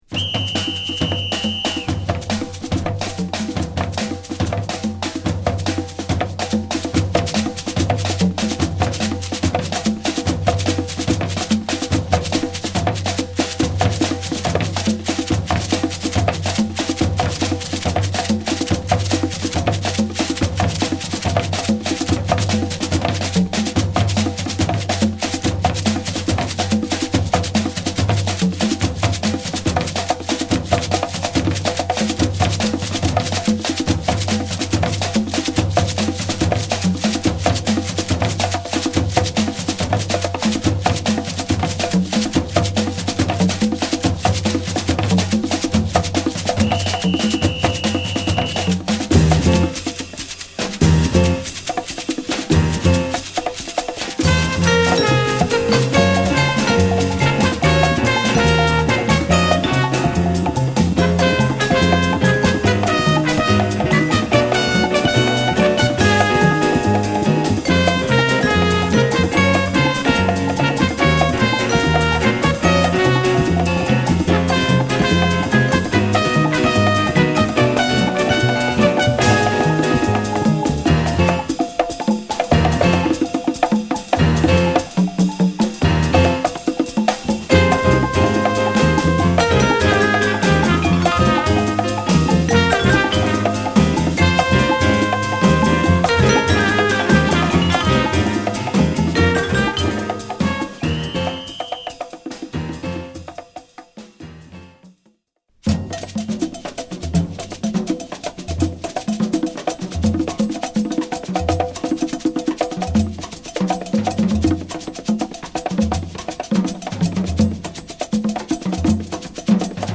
Jazz Funk , Jazz Walts , Rare Groove